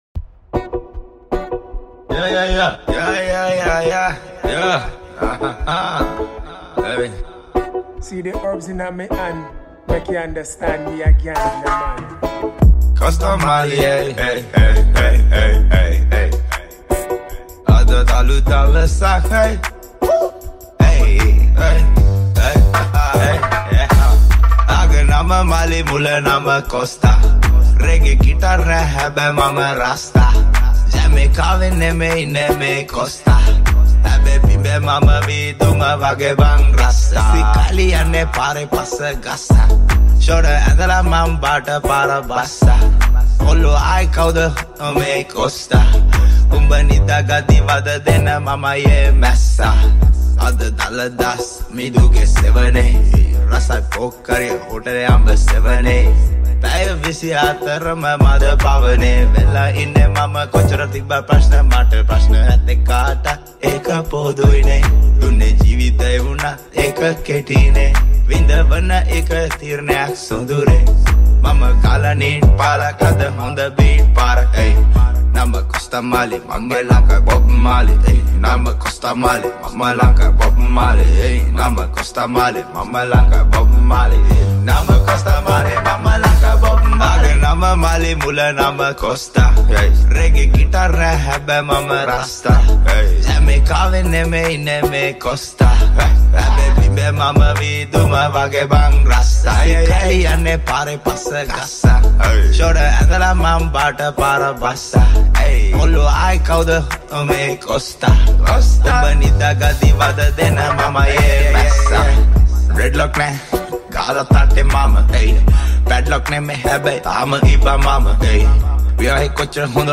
remix
Rap